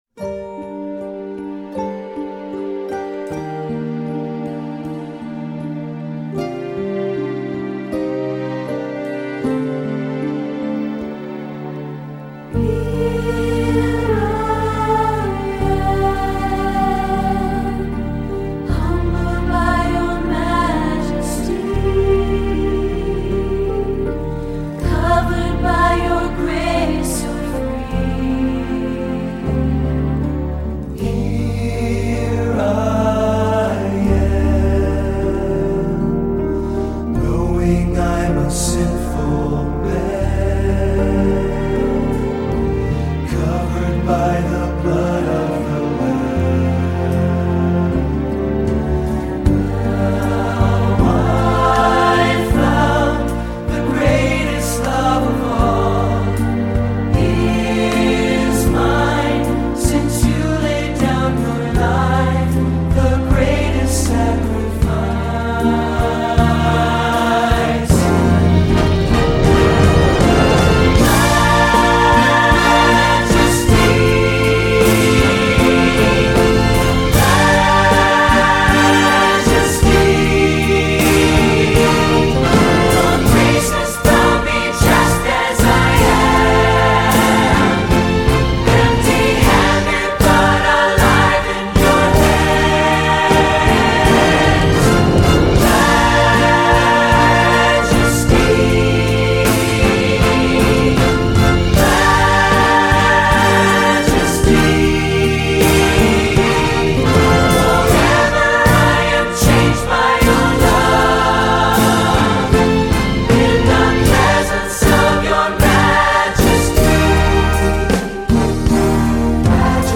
Summer Choir Anthems